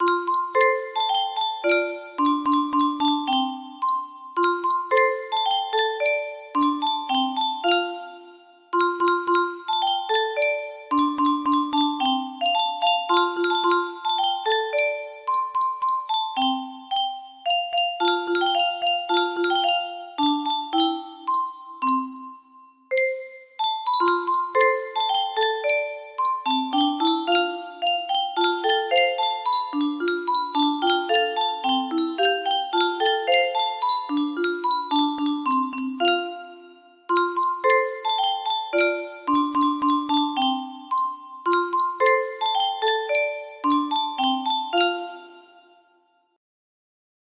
Happy Song music box melody